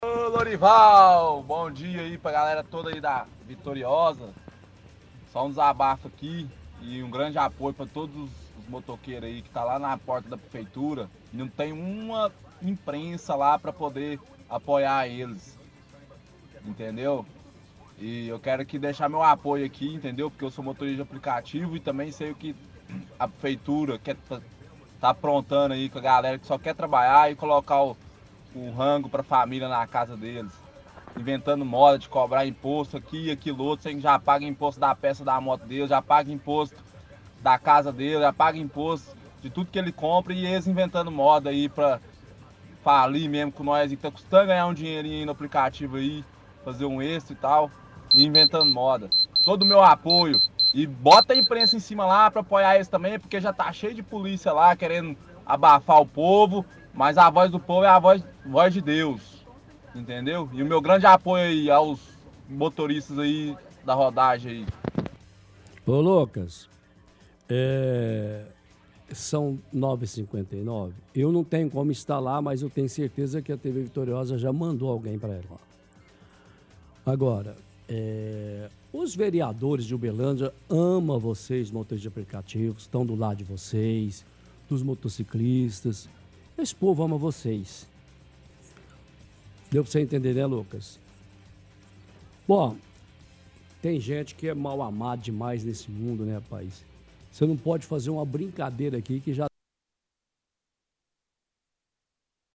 – Ouvinte fala sobre uma manifestação acontecendo na porta da prefeitura organizada por motoristas de aplicativo em motocicletas.